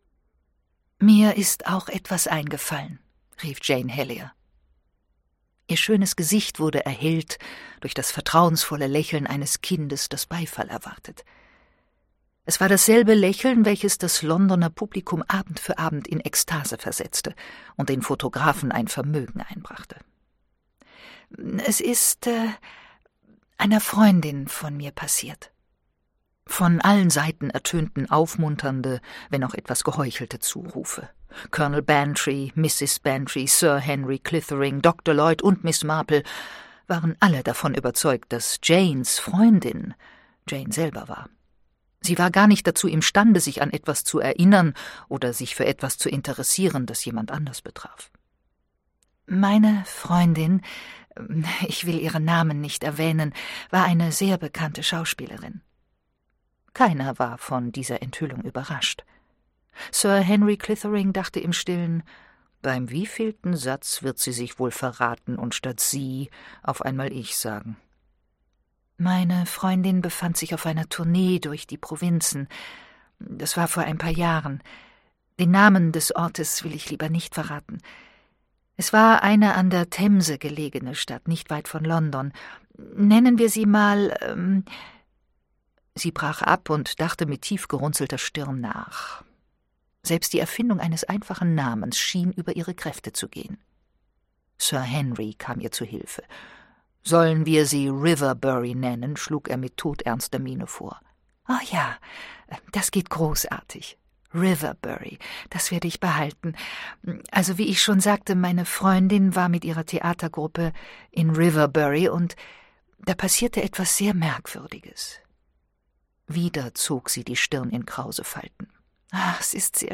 Miss Marple – Alle 12 Fälle Vollständige Lesung